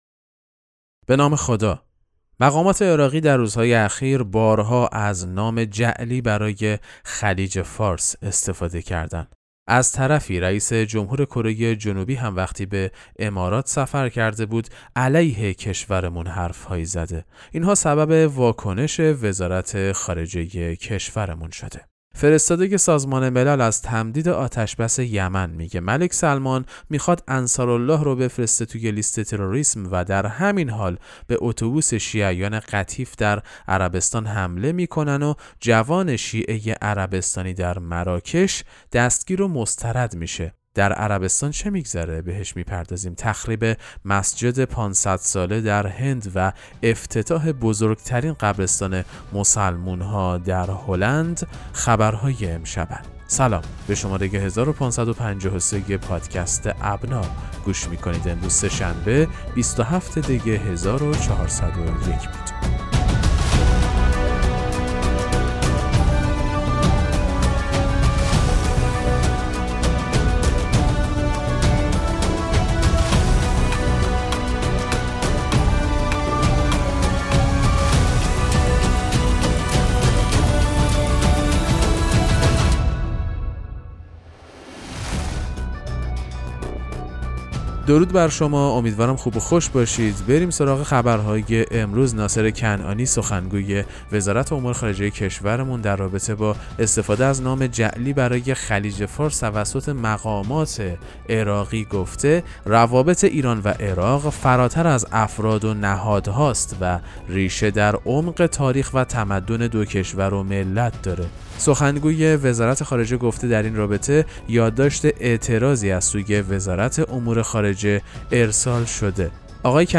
خبرگزاری اهل‌بیت(ع) ـ ابنا ـ با ارائه سرویس «پادکست مهم‌ترین اخبار» به مخاطبان خود این امکان را می‌دهد که در دقایقی کوتاه، از مهم‌ترین اخبار مرتبط با شیعیان جهان مطلع گردند. در زیر، پادکست اخبار امروز سه‌شنبه 27 دی 1401 را به مدت 6 دقیقه و 50 ثانیه بشنوید: